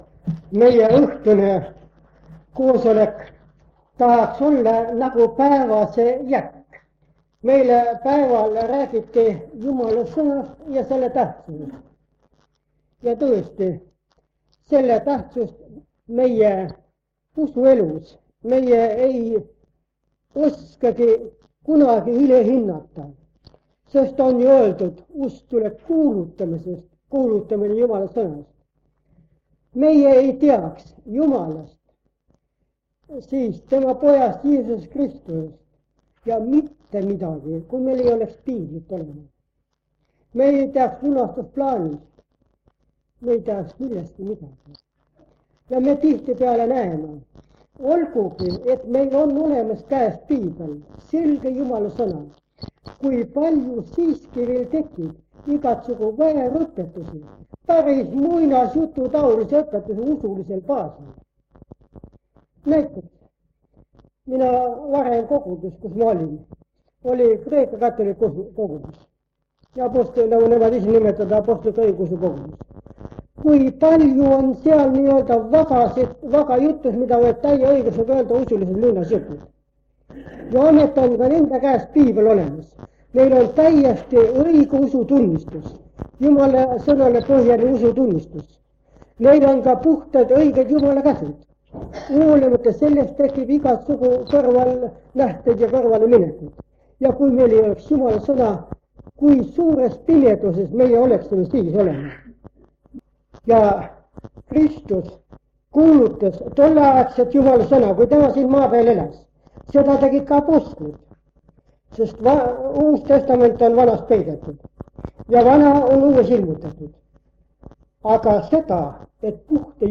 Jutlus vanalt lintmaki lindilt.
Jutlused